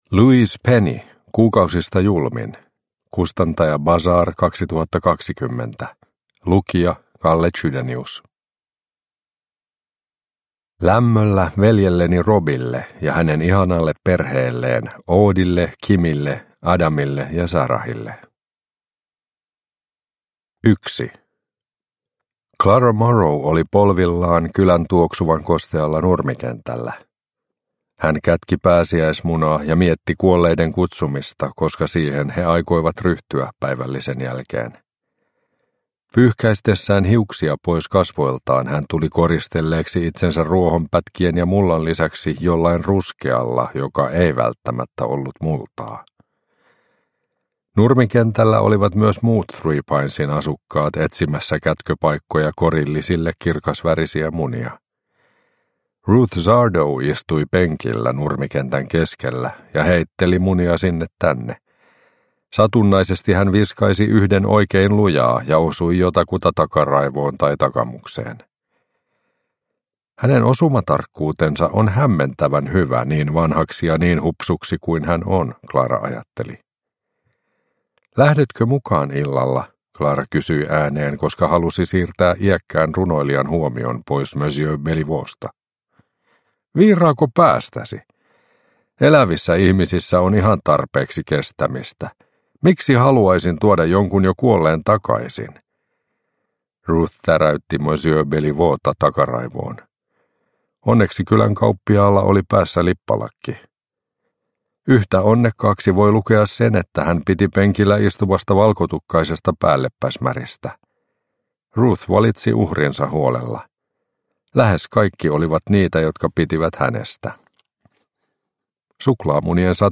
Kuukausista julmin – Ljudbok – Laddas ner